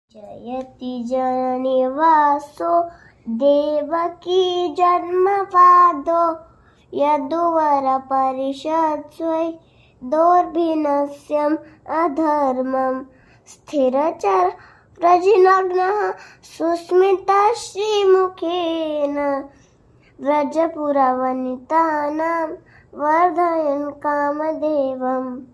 On rising, some chant jayati jana-nivāso devakī-janma-vādo.
I am in Kurmagrama, a traditional village in Andhra Pradesh.2